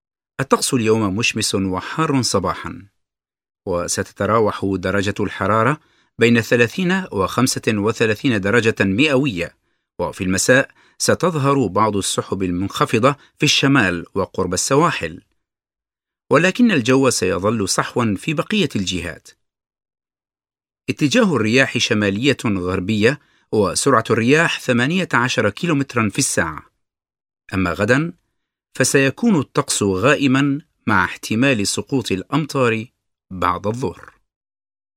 Прогноз погоды по-арабски